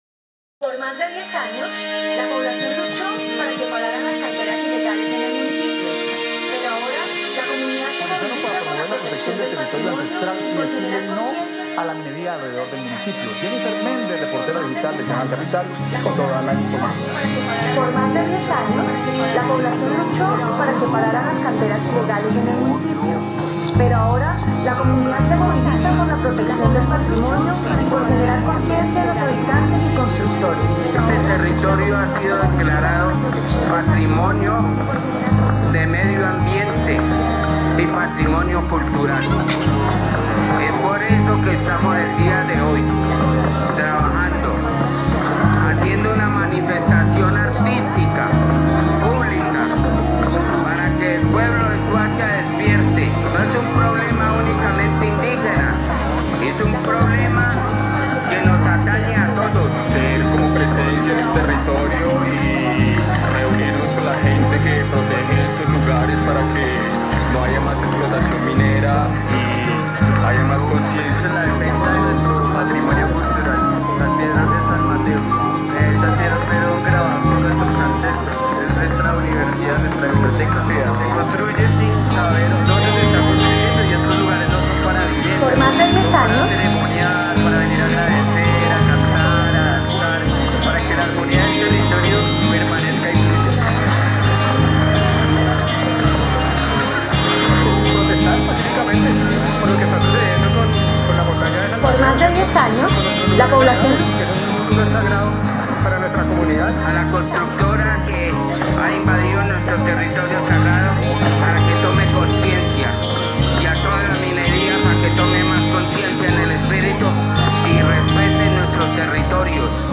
Paisaje Sonoro, Identidad Cultural, Patrimonio Ancestral, Conciencia y Recursos